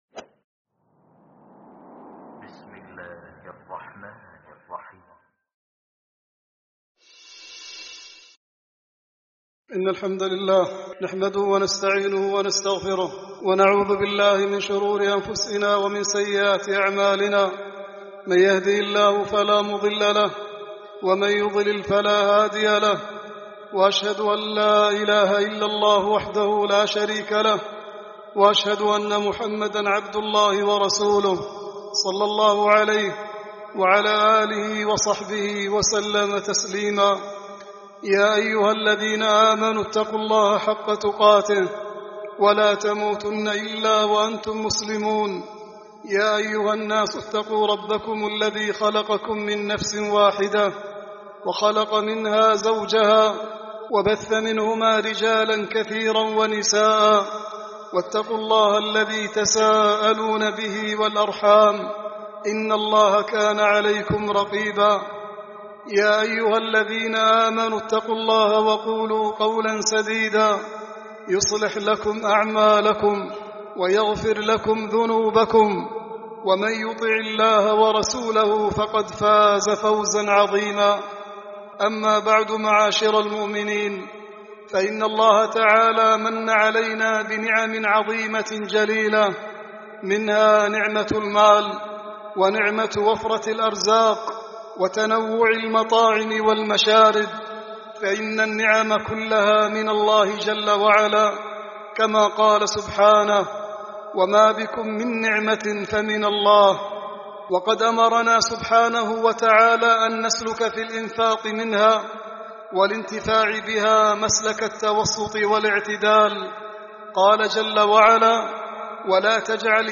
خطبة التحذير من التبذير في الولائم